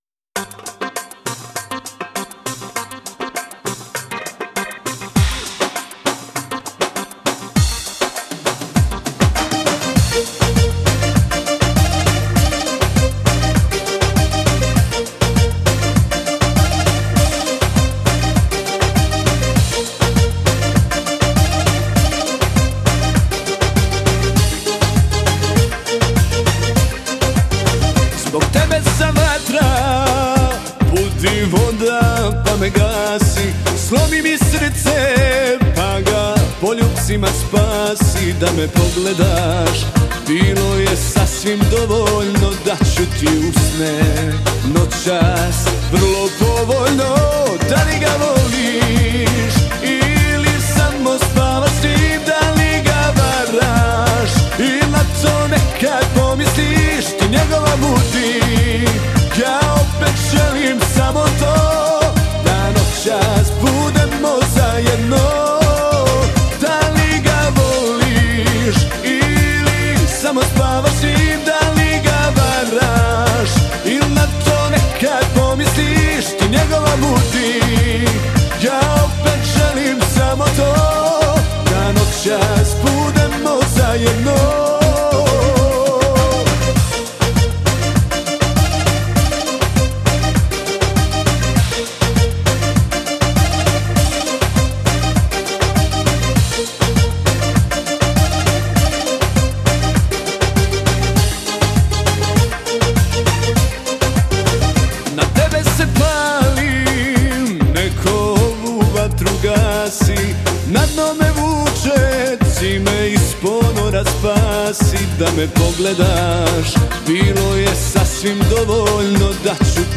Для любителей современной сербской эстрадной музыки.